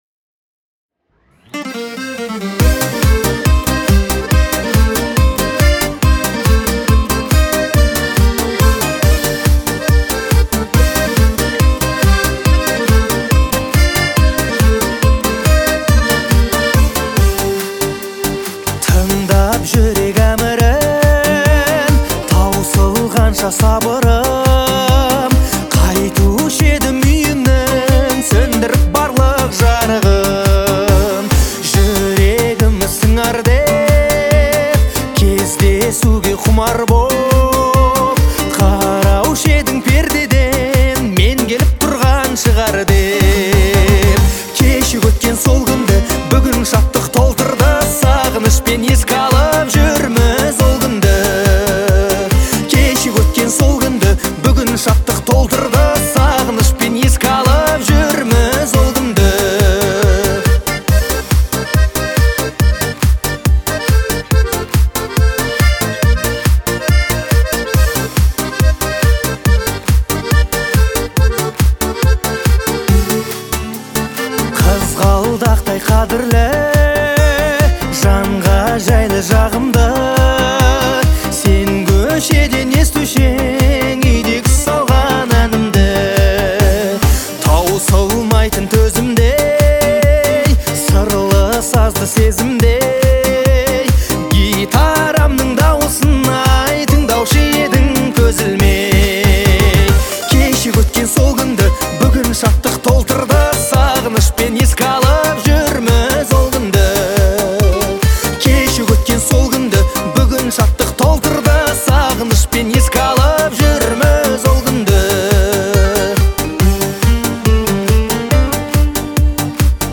представляет собой яркий пример казахской поп-музыки.
нежный и выразительный вокал